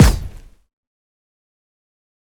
AFRICAN STOMP KICK.wav